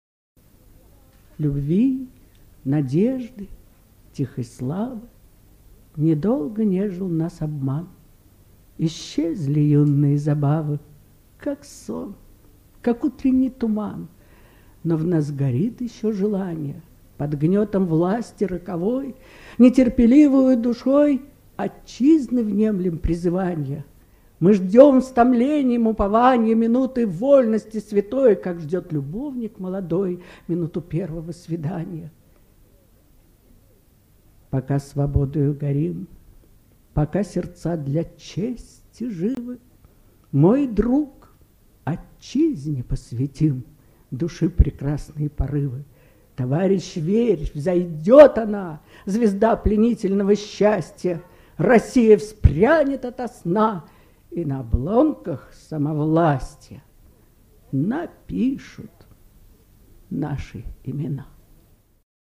Татьяна КОНЮХОВА читает любимое стихотворение Георгия Юматова.